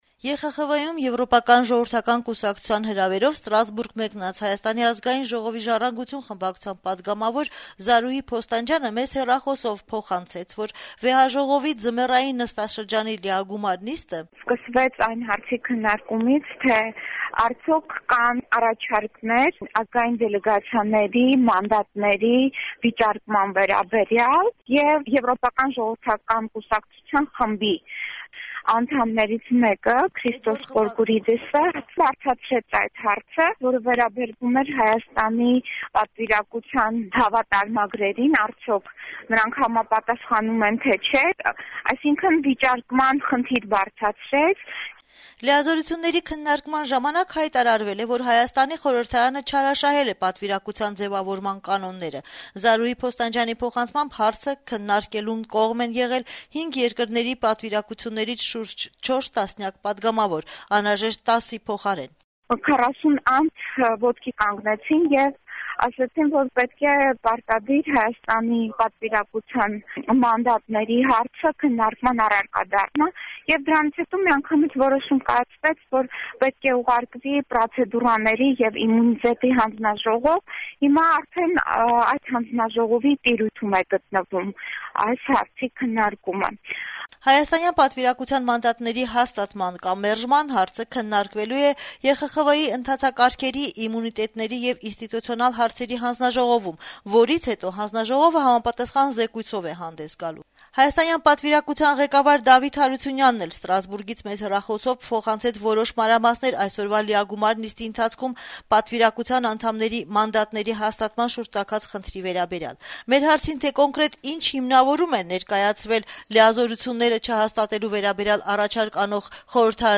Ստրասբուրգում երկուշաբթի օրը մեկնարկած Եվրախորհրդի խորհրդարանական վեհաժողովի (ԵԽԽՎ) լիագումար նիստում, ինչպես «Ազատություն» ռադիոկայանին Ստրասբուրգից հեռախոսով փոխանցեց Հայաստանի խորհրդարանի «Ժառանգություն» խմբակցության անդամ Զարուհի Փոստանջյանը, վեհաժողովի նախագահ է ընտրվել Թուրքիայի ներկայացուցիչ Մեւլյութ Չավուշօղլուն: